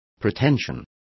Complete with pronunciation of the translation of pretensions.